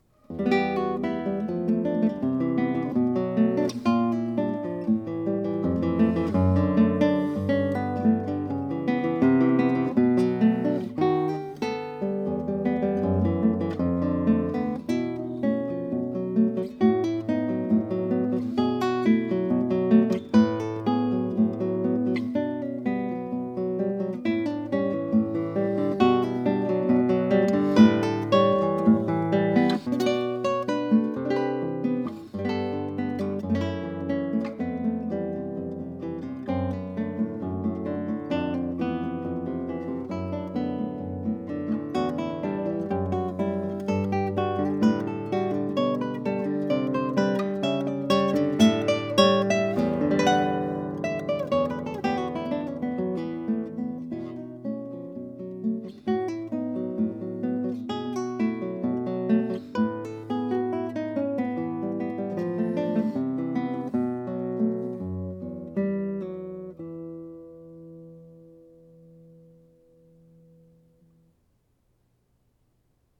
21 short etude-like movements, total length ca. 27 minutes, written in 2013.